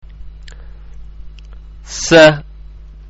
ഉച്ചാരണം (പ്ലേ ബട്ടണ്‍ അമര്‍ത്തുക)